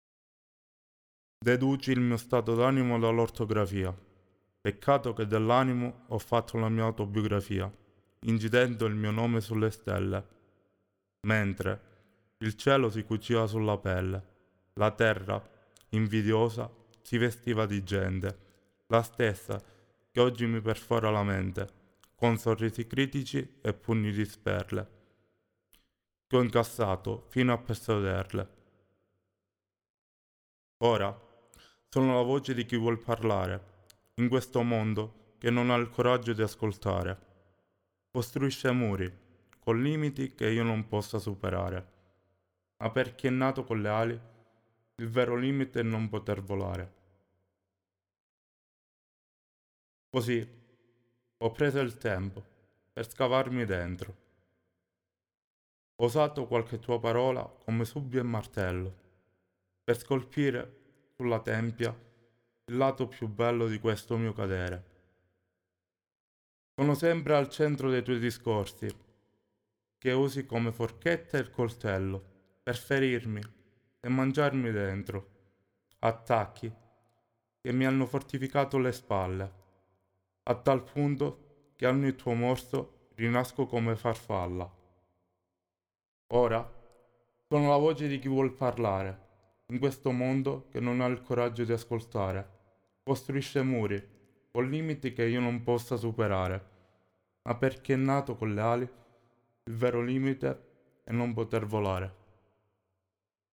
ecco i bianchi (solo voce)